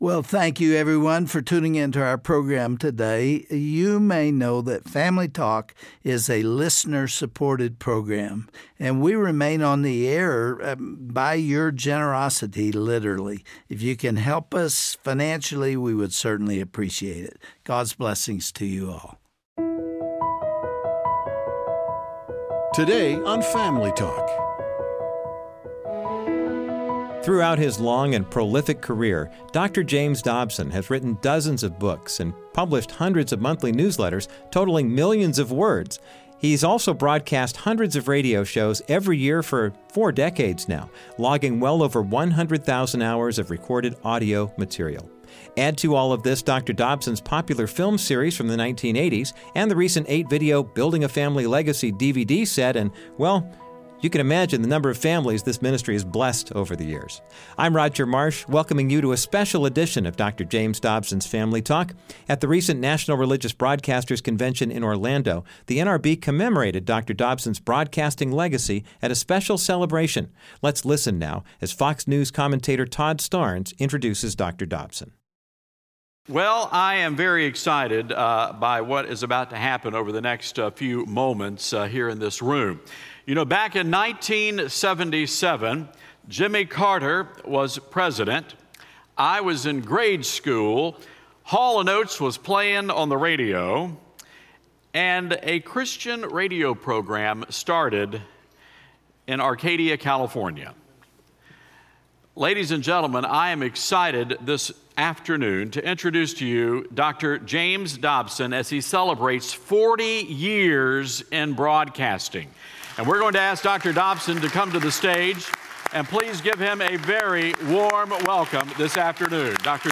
On todays broadcast of Dr. James Dobsons Family Talk, Dr. Dobson himself is interviewed by Todd Starnes of Fox News about how he responded to the call of God and followed even when the road ahead looked impossible. Hear never before told inspiring stories about Gods sustaining faithfulness in Dr. Dobsons life, today on Dr. James Dobsons Family Talk.